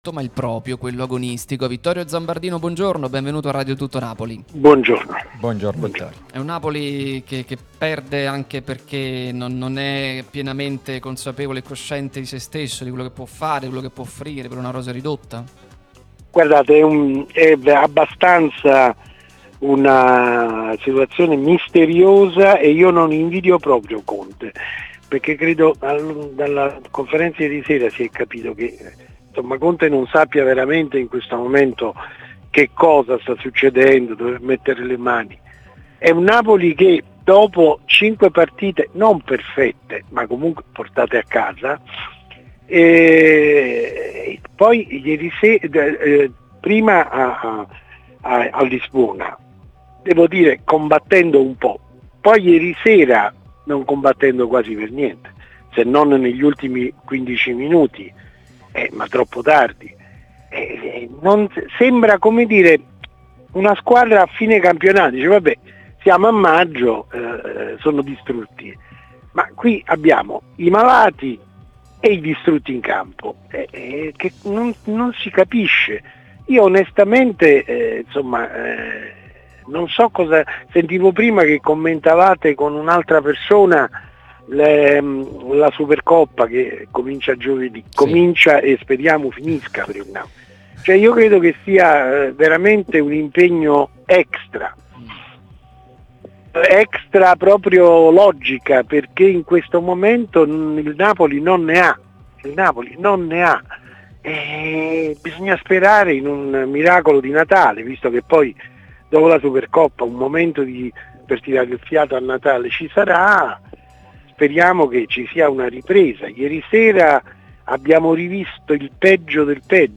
trasmissione sulla nostra Radio Tutto Napoli, prima radio tematica...